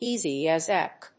アクセント(as) éasy as ÁBĆ